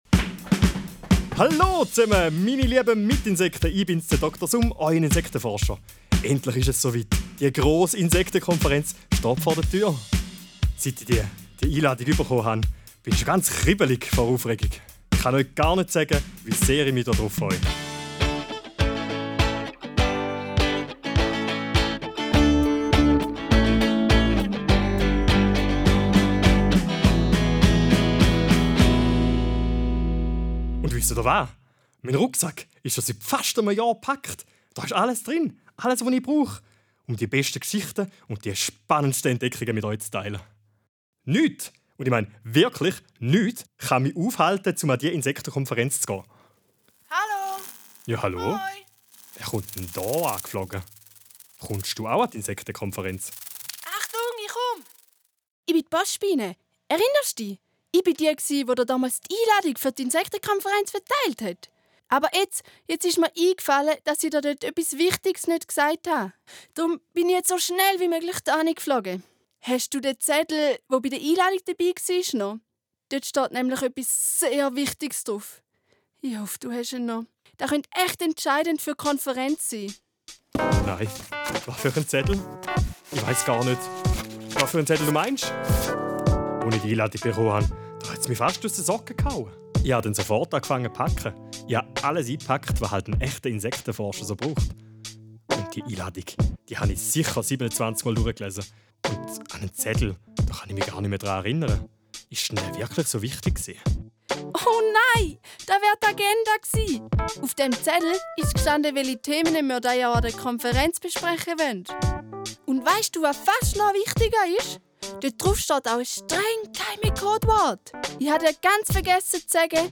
Spielt nun das erste Hörspiel ab.